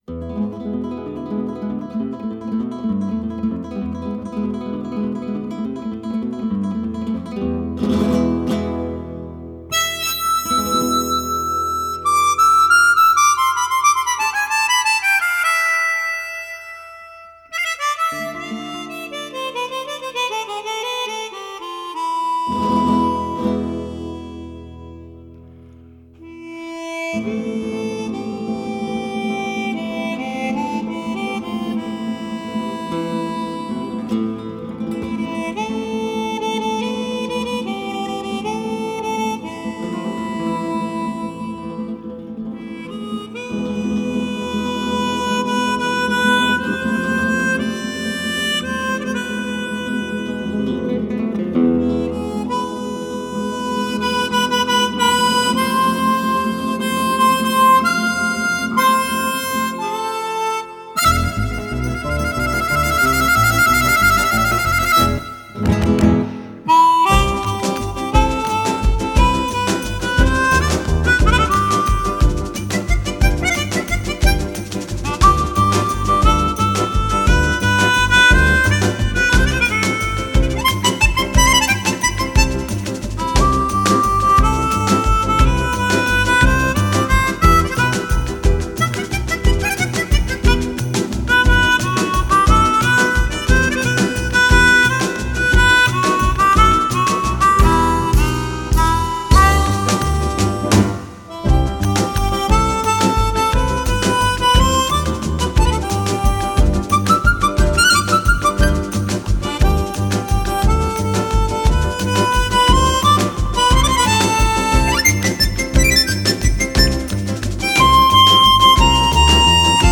口琴演奏